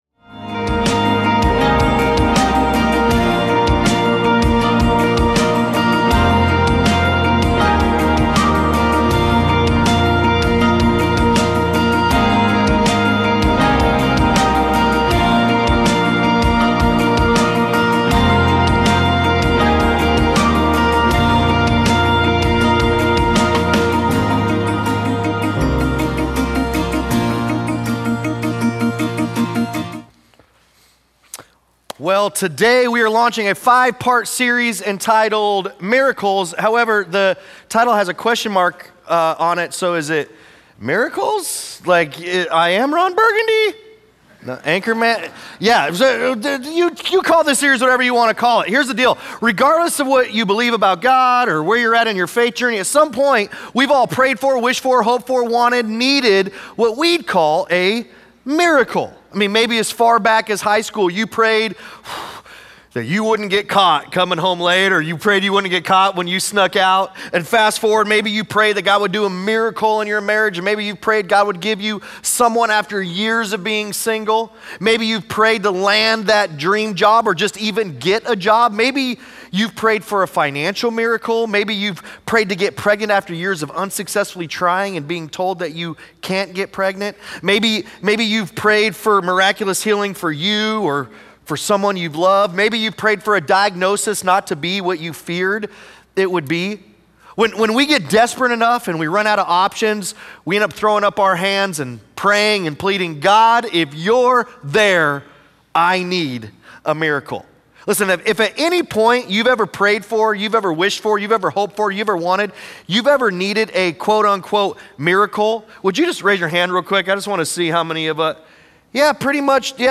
Sunday Sermons Miracles, Week 1: "Do Miracles Exist?"